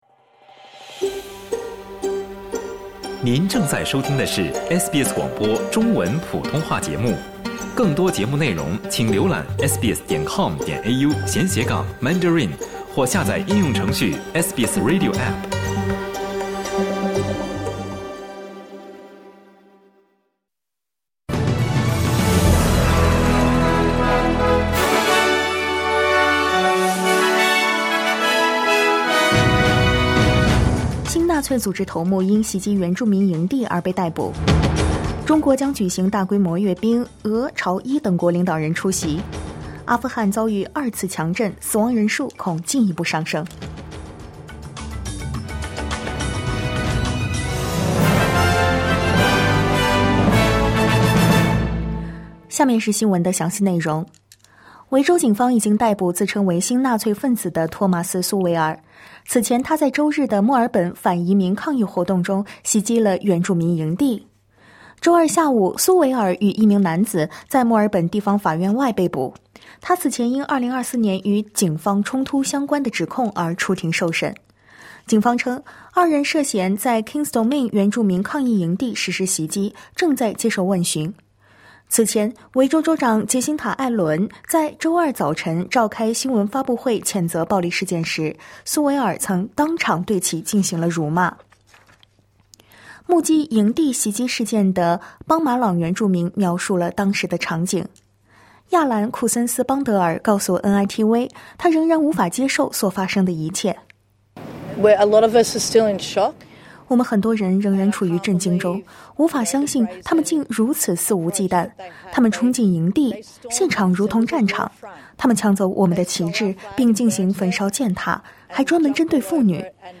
SBS早新闻（2025年9月3日）